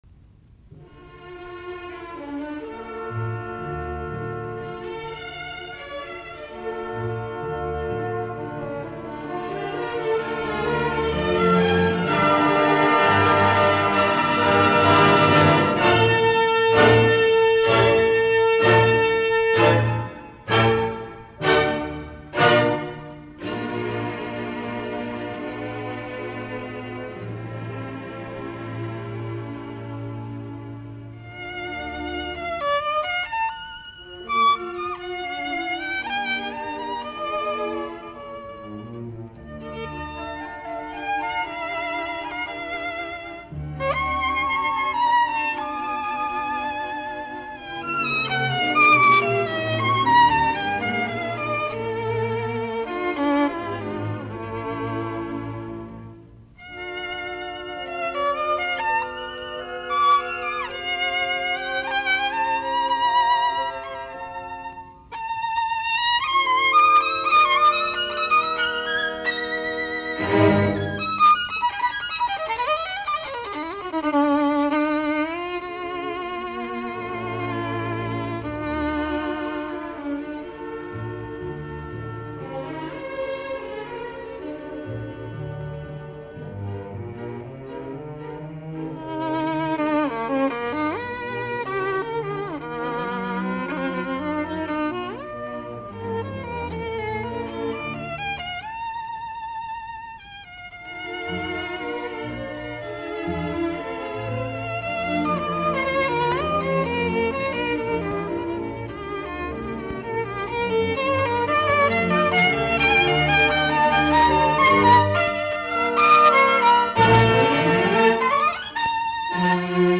Konsertot, viulu, ork., nro 2, op22, d-molli
Allegro moderato